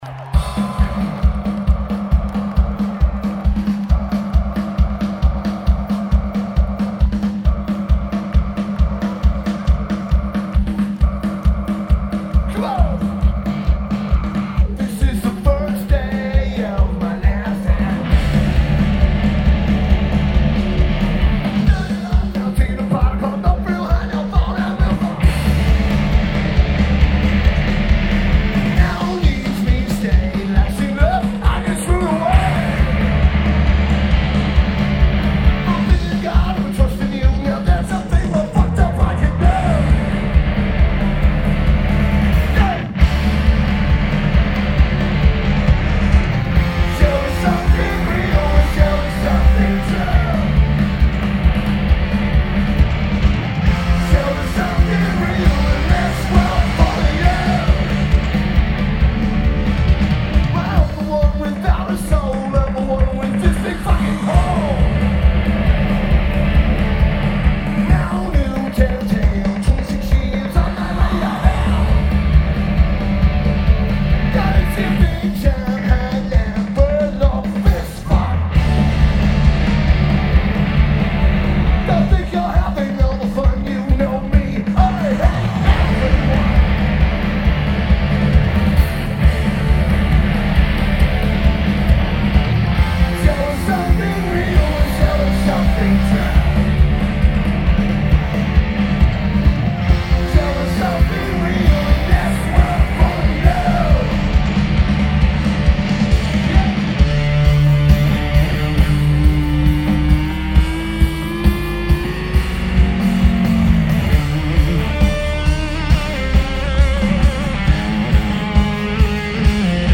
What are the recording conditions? Charter One Pavillion Lineage: Audio - AUD (Schoeps MK4s + Nbox + Edirol R-09) Notes: Recorded 60' from the right stack.